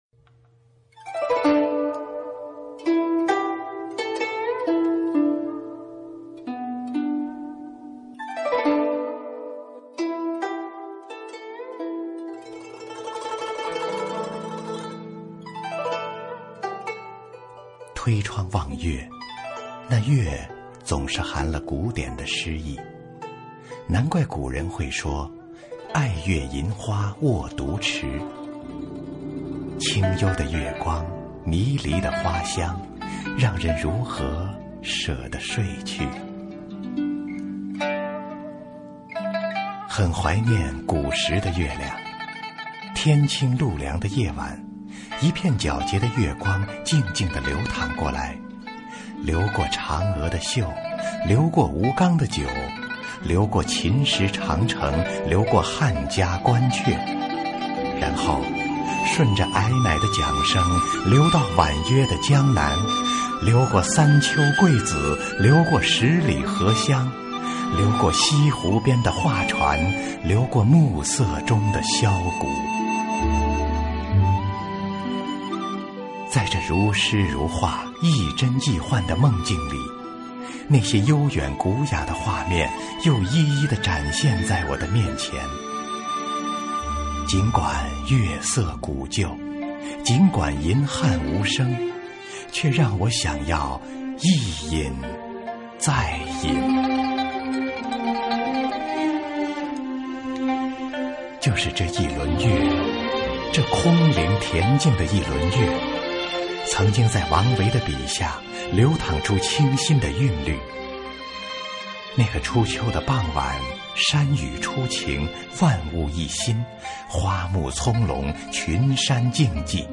经典朗诵欣赏
新派朗诵——用声音阐释情感